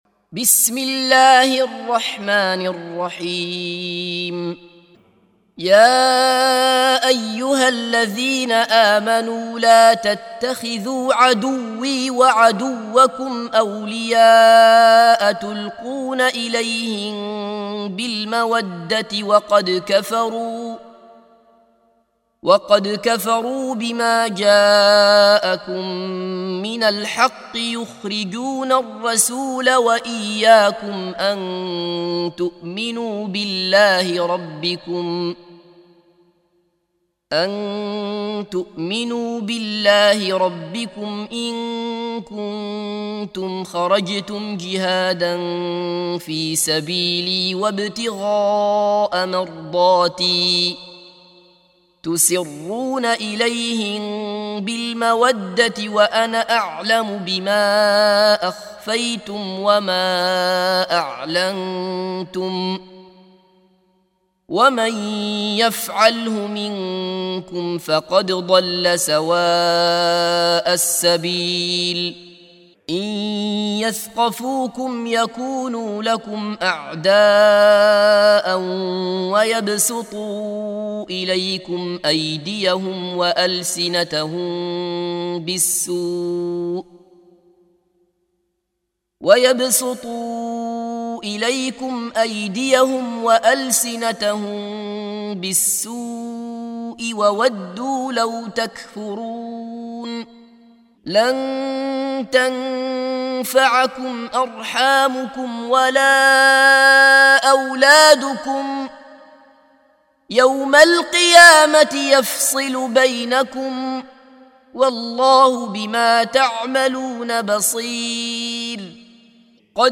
سُورَةُ المُمۡتَحنَةِ بصوت الشيخ عبدالله بصفر